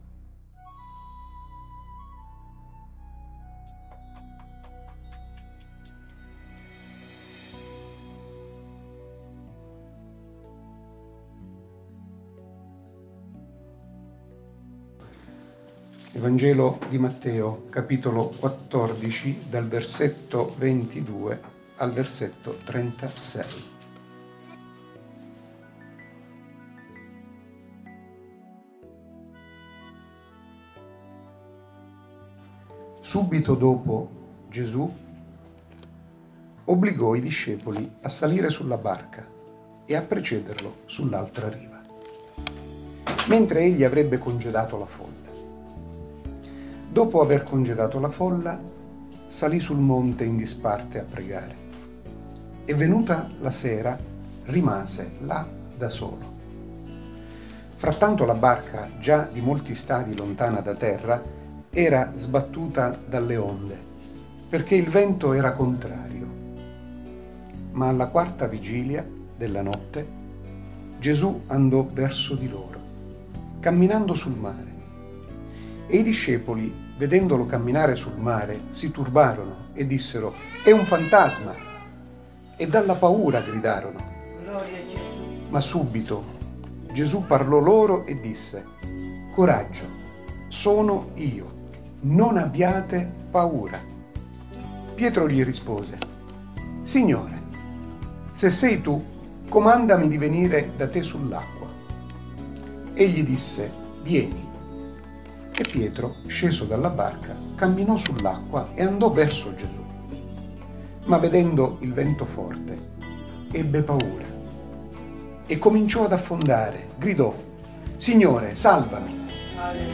Letture della Parola di Dio ai culti della domenica